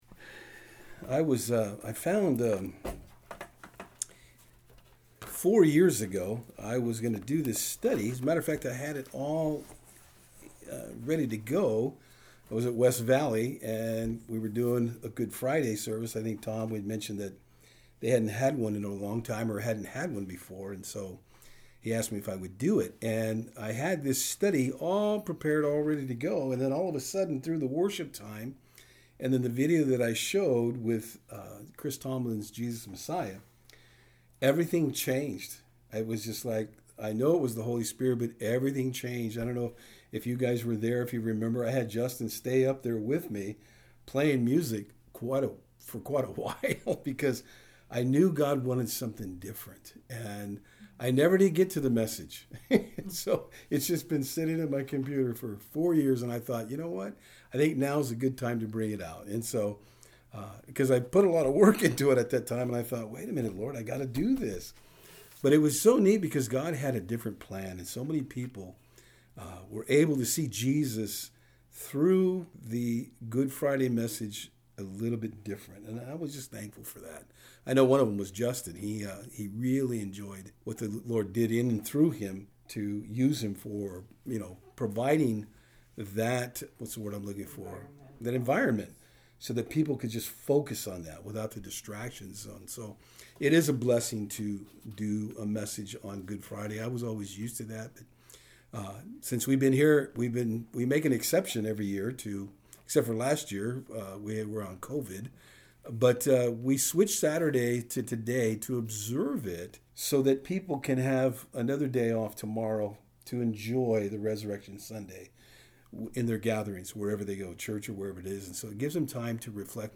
Service Type: Good Friday Message
4-3-21-Good-Friday-Message.mp3